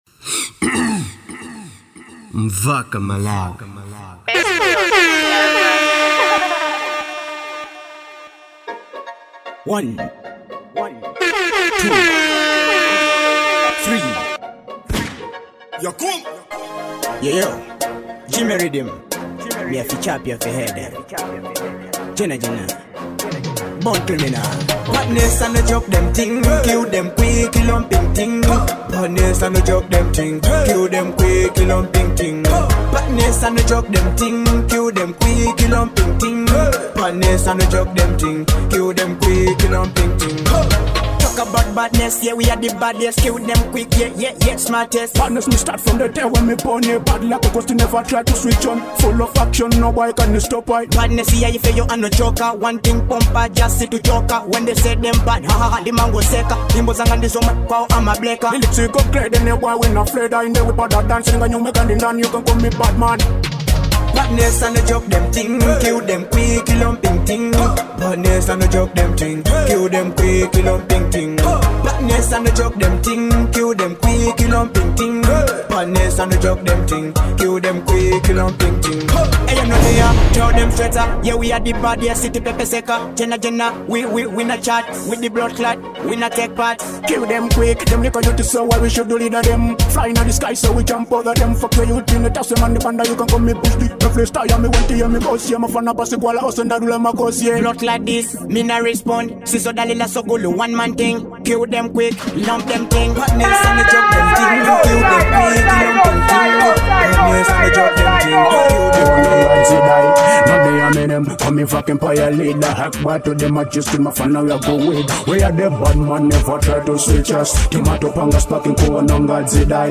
type: Dancehall mixtape